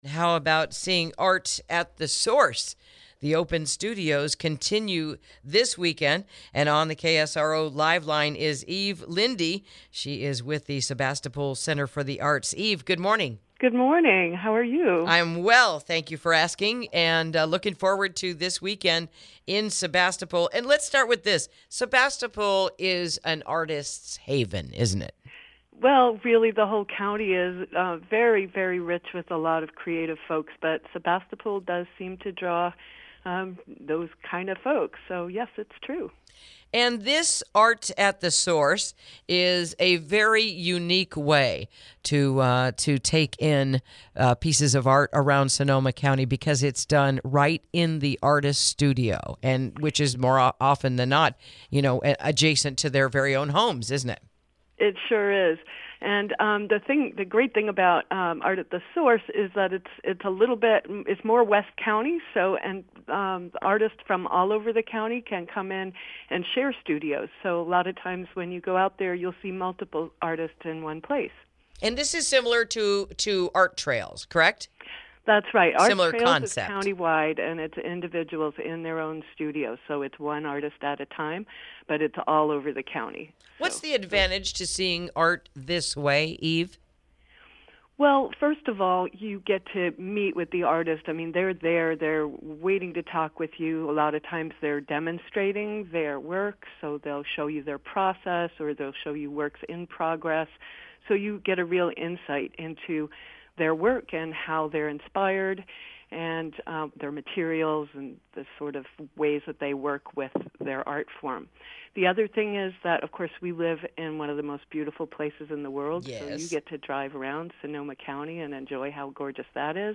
Interview: Sebastopol's Center for the Arts – Arts at the Source This Weekend | KSRO 103.5FM 96.9FM & 1350AM